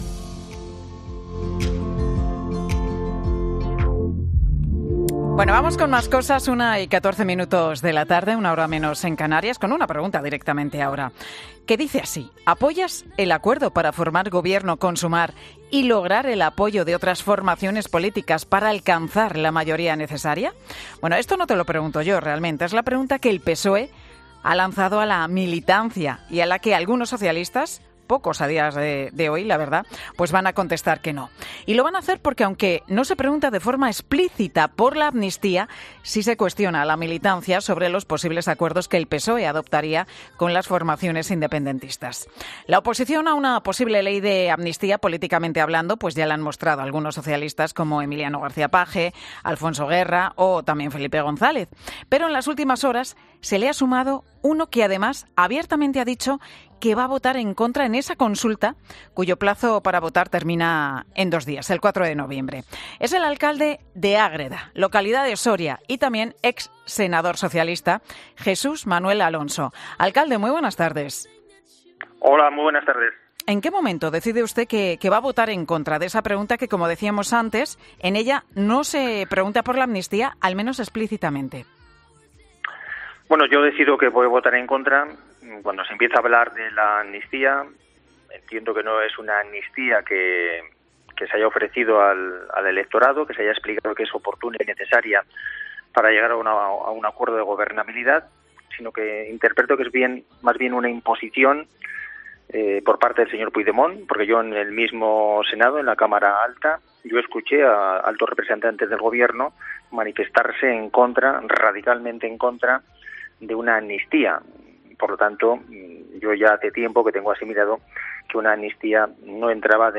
Jesús Manuel Alonso, alcalde socialista de Ágreda, en Mediodía COPE, confirma que votará no amnistía